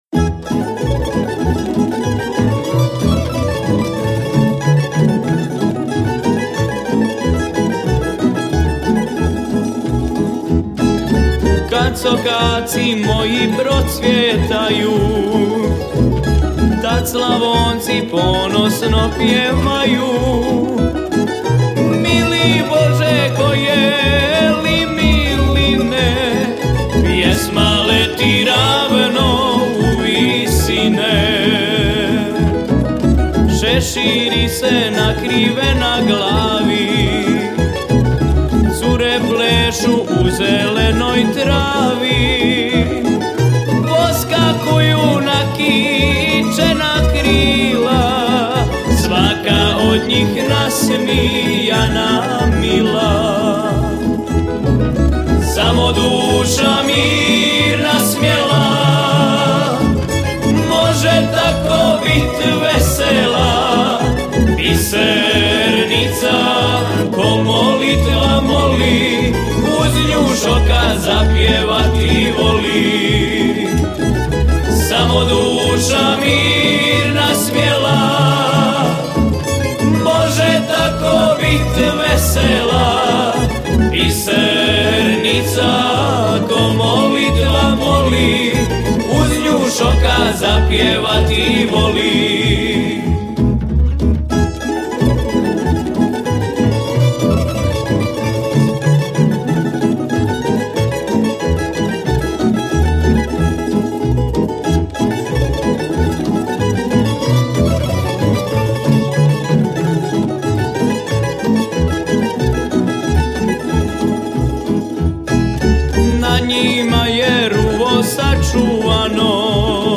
Na festivalu je ove godine nastupilo 19 izvođača s novim autorskim pjesmama, od toga je bilo 8 tamburaških sastava te 11 solista.